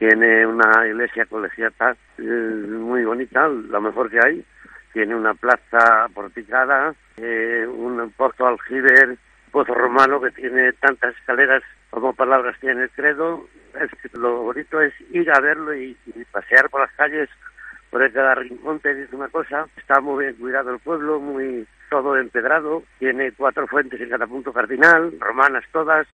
Honorio Rico, el alcalde de Bonilla de la Sierra.
Lo ha contado en COPE Honorio Rico, el alcalde de Bonilla de la Sierra.